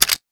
weapon_foley_pickup_04.wav